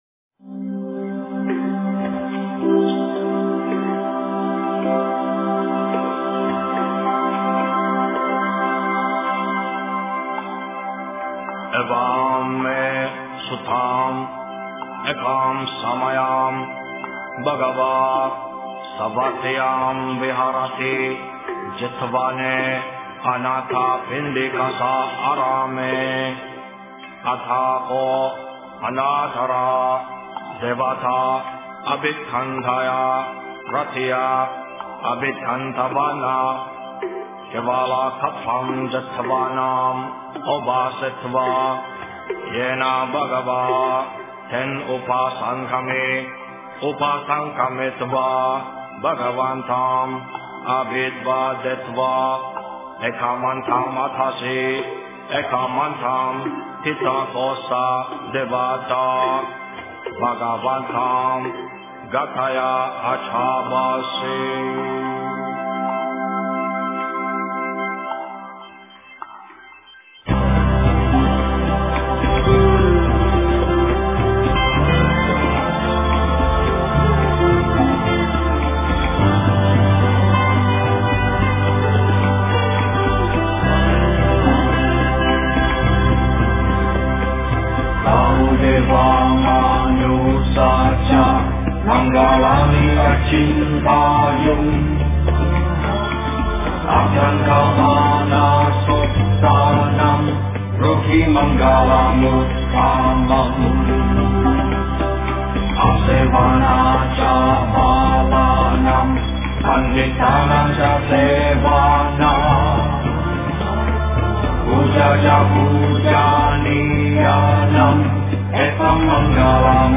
吉祥经 诵经 吉祥经--新韵传音 点我： 标签: 佛音 诵经 佛教音乐 返回列表 上一篇： 无量寿经-上 下一篇： 地藏经-分身集会品第二 相关文章 圆觉经-06清净慧菩萨 圆觉经-06清净慧菩萨--未知...